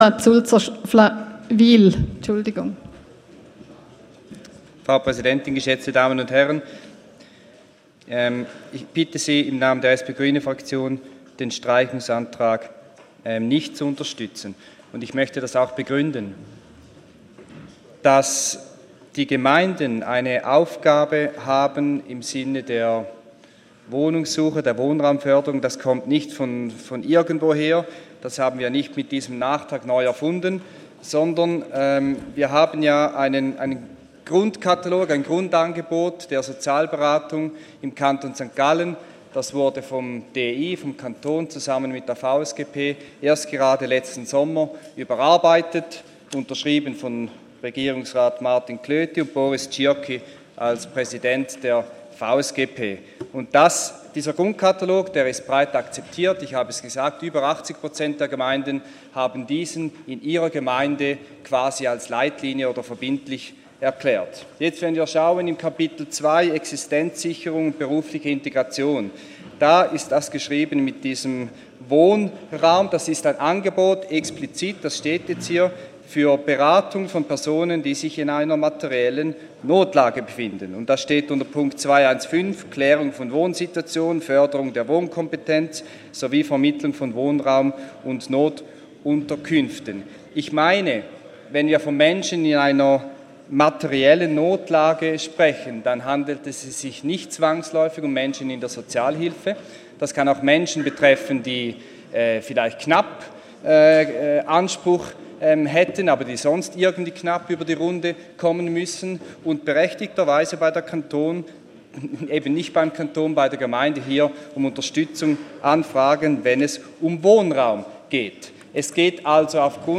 17.9.2018Wortmeldung
Session des Kantonsrates vom 17. bis 19. September 2018